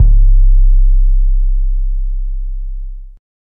Metro Bass Heavy_808.wav